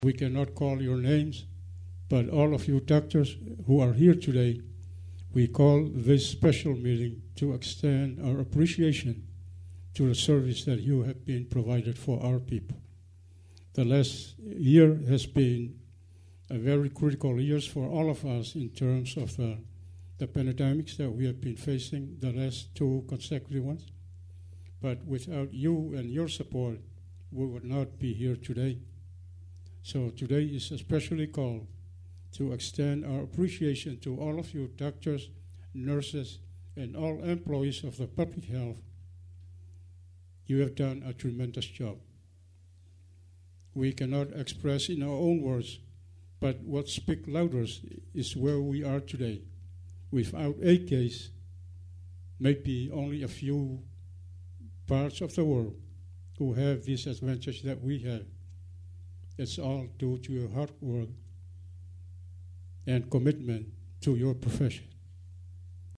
Governor Lolo Moliga said at the meeting, which was attended by many doctors that he and Lemanu had called the meeting especially to thank them for their dedication and commitment which has kept American Samoa covid free.